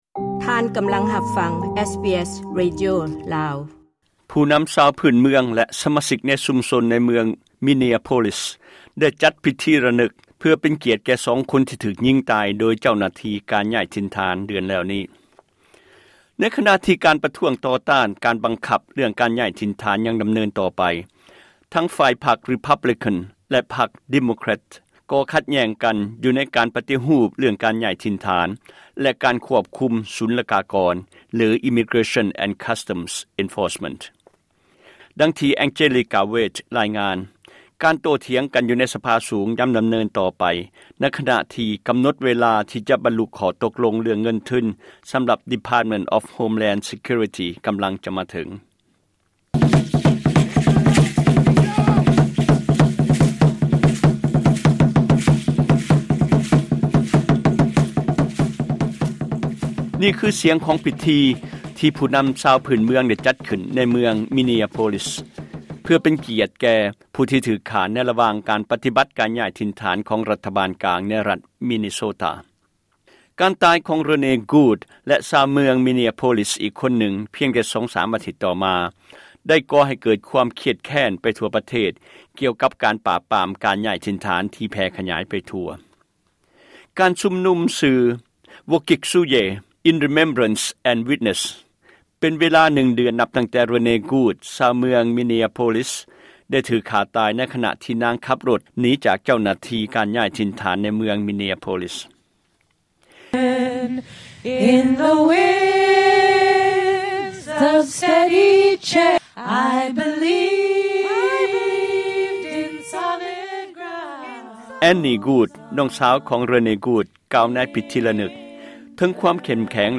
ການໂຕ້ຖຽງກັນໃນສະພາສູງ ຍັງດໍາເນີນຕໍ່ໄປ ໃນຂນະທີ່ກໍານົດເວລາ ທີ່ຈະບັນລຸຂໍ້ຕົກລົງ ເລື່ອງເງິນທຶນ ສໍາລັບ Department of Homeland Security ກຳລັງຈະມາເຖິງ. ນີ້ຄື ສຽງຂອງພິທີ ທີ່ຜູ້ນໍາຊາວພື້ນເມືອງ ໄດ້ຈັດຂຶ້ນ ໃນເມືອງ Minneapolis , ເພື່ອເປັນກຽດແກ່ ຜູ້ທີ່ຖືກຂ້າ ໃນລະຫວ່າງການປະຕິບັດການຍ້າຍຖິ່ນຖານ ຂອງຣັດຖະບານກາງໃນຣັດ Minnesota.